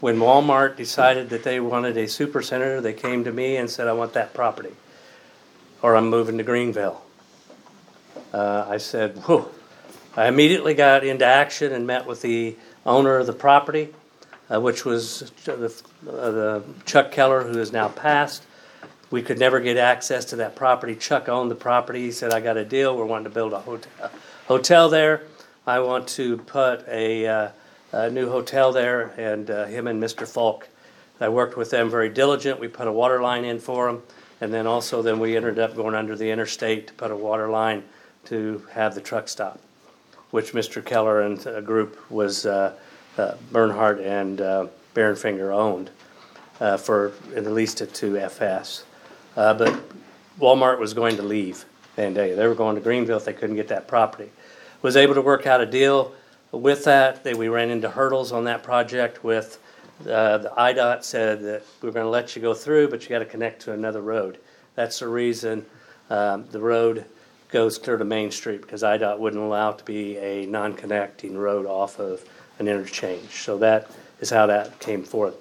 During the Mayor’s report, Mayor Gottman said that he was very thankful to the voters for re-electing him to six terms in office.  And, he also told about some different happenings during his time as Mayor—including one about the Wal-Mart Supercenter and expansion at the west interchange early in his time as Mayor.